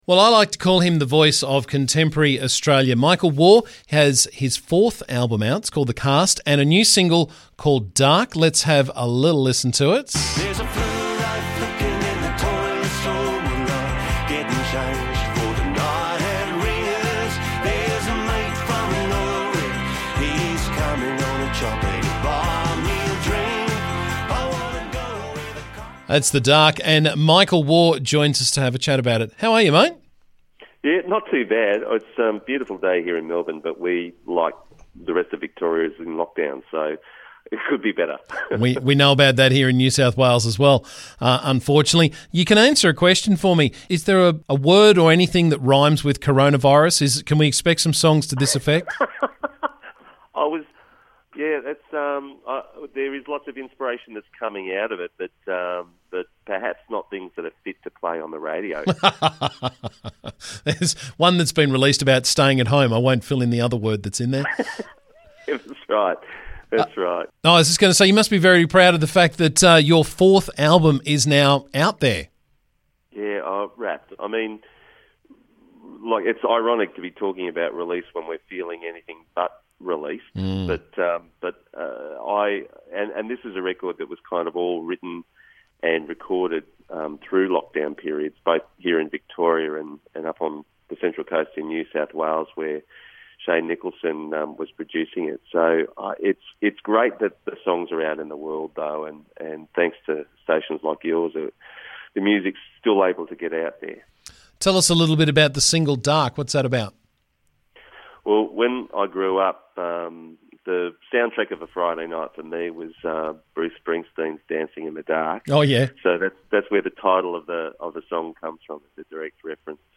Aussie singer-songwriter has a new album and single out and he was on the show this morning to talk about them as well as his choice of drink many years ago.